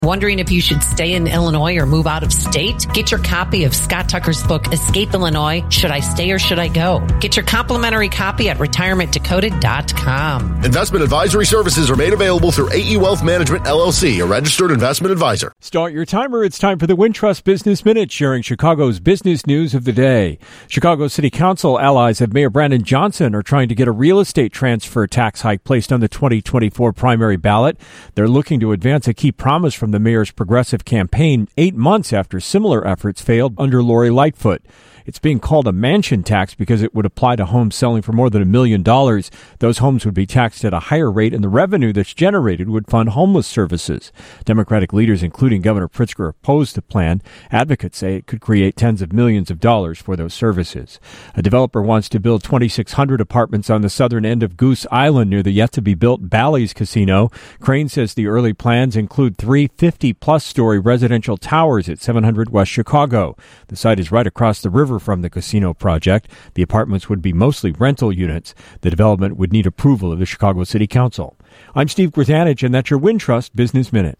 business news of the day